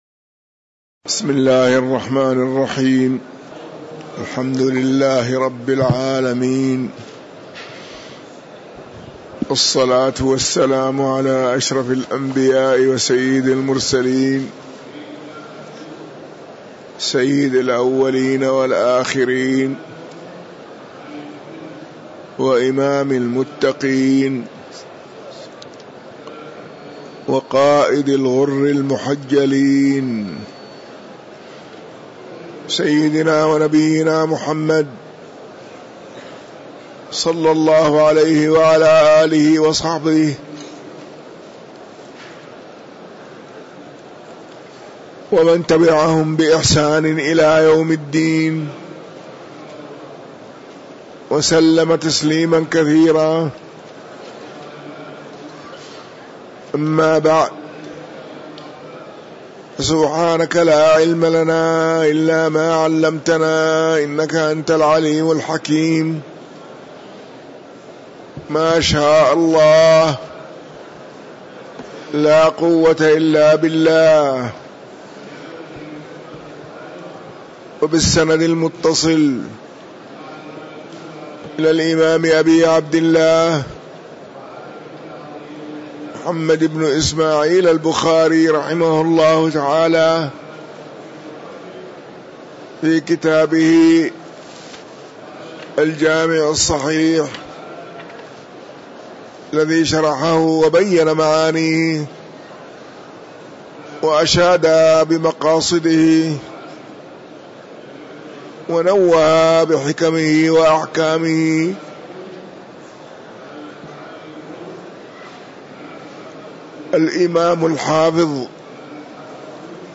تاريخ النشر ١٨ محرم ١٤٤٤ هـ المكان: المسجد النبوي الشيخ